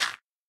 25w18a / assets / minecraft / sounds / dig / gravel2.ogg
gravel2.ogg